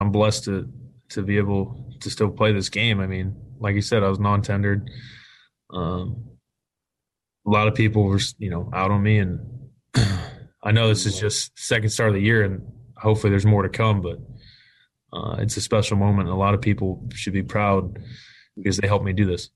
After the game Rodon spoke to the media about what the moment meant to him and his team.